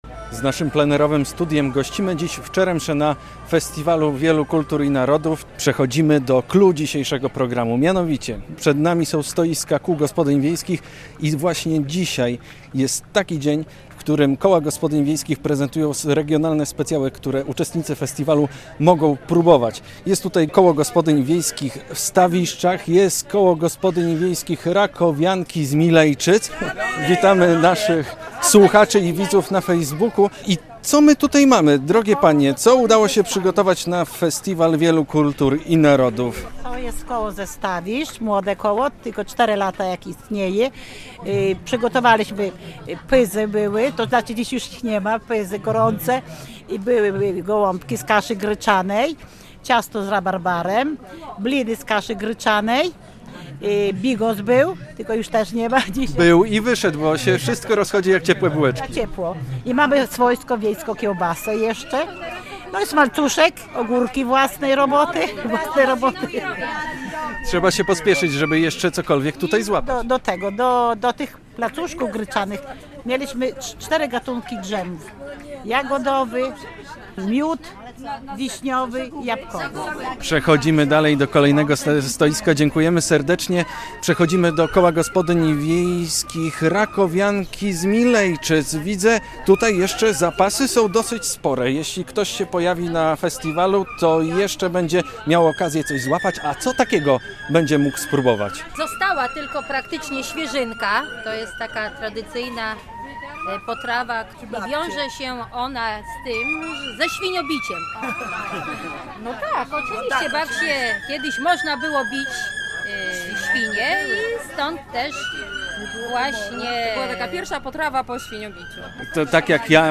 Plenerowe studio Polskiego Radia Białystok stanęło na terenie Festiwalu Wielu Kultur i Narodów.